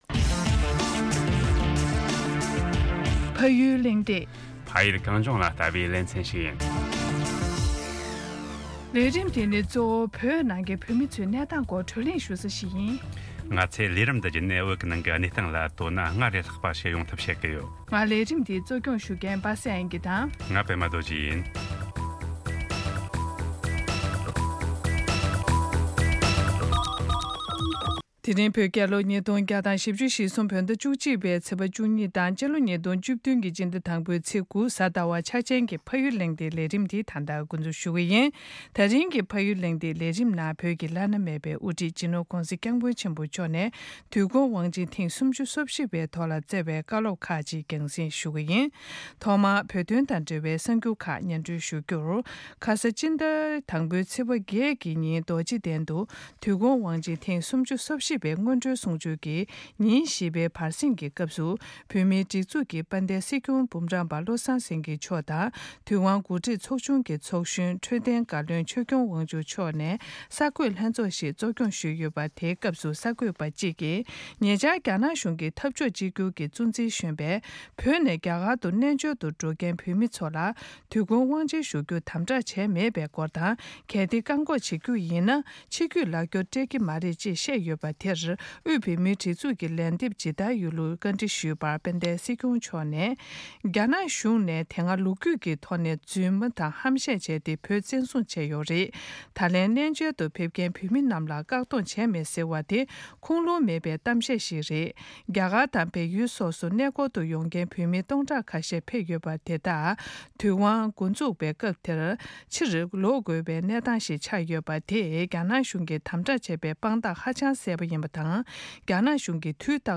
དུས་འཁོར་དབང་ཆེན་༣༤པའི་ཐོག་༧གོང་ས་མཆོག་གི་བཀའ་སློབ་ཁག་ཅིག